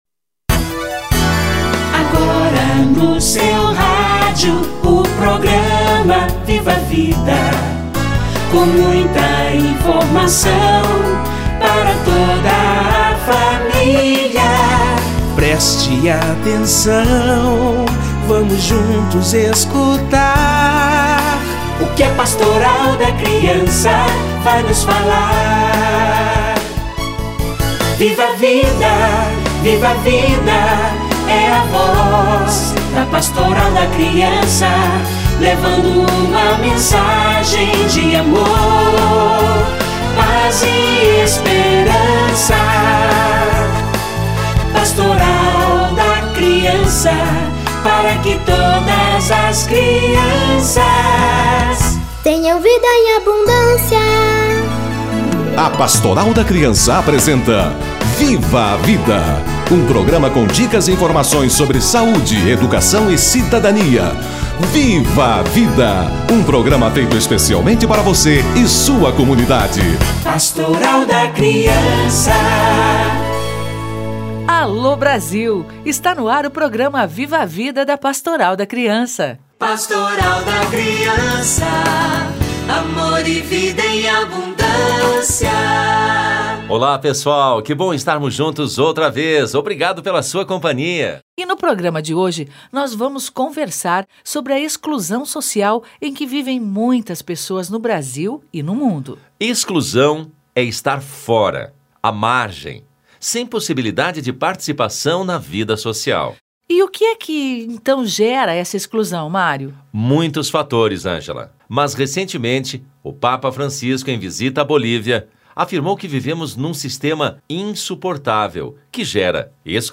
Meio ambiente e o Grito dos Excluídos - Entrevista